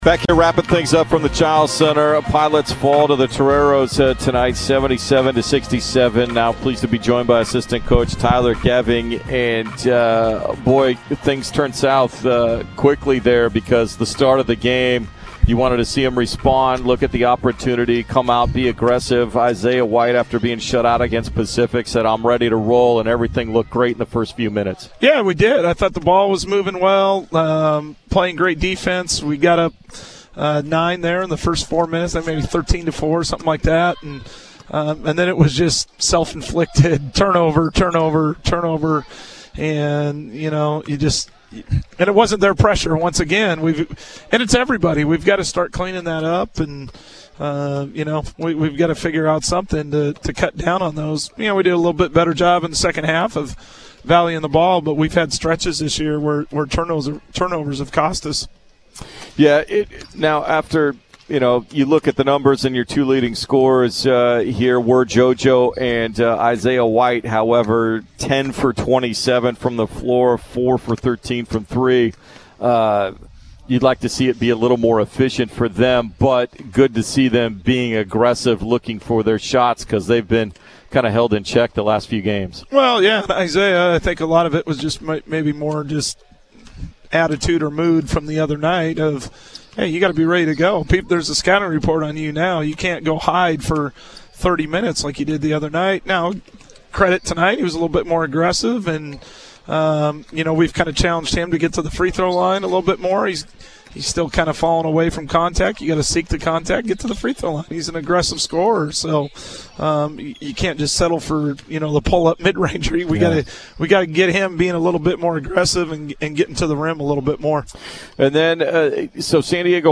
Men's Hoops Post-Game Interview vs. San Diego